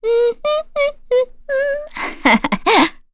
Erze-Vox_Hum.wav